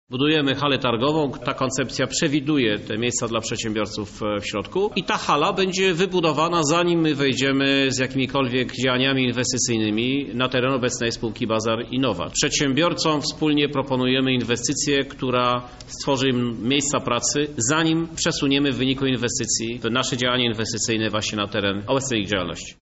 Sprawę tłumaczy Krzysztof Żuk – prezydent Lublina.